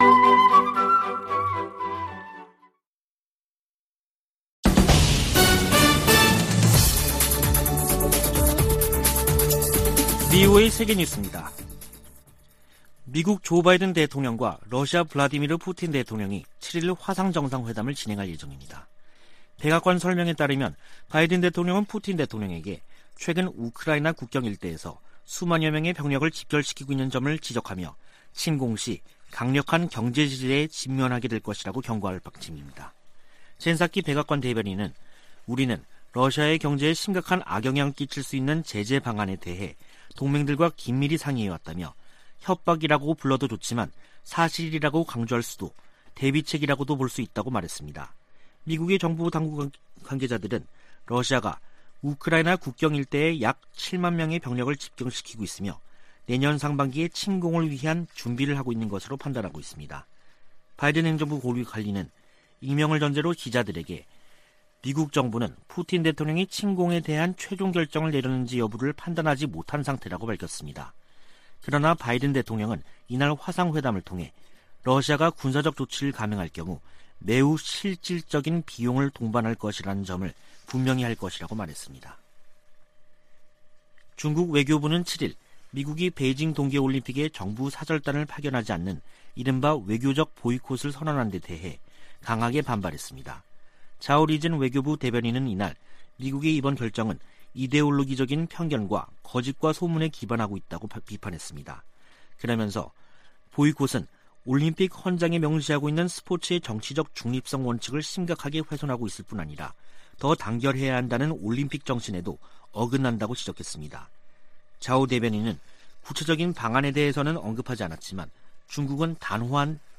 VOA 한국어 간판 뉴스 프로그램 '뉴스 투데이', 2021년 12월 7일 3부 방송입니다. 미국이 중국의 인권 탄압을 이유로 내년 2월 베이징 동계올림픽에 정부 공식대표단을 파견하지 않는다고 공식 발표했습니다. 유럽연합(EU)이 북한인 2명과 기관 1곳 등에 인권제재를 1년 연장했습니다. 조 바이든 미국 대통령이 오는 9일과 10일 한국과 일본 등 전 세계 110개국이 참가하는 민주주의 정상회의를 화상으로 개최합니다.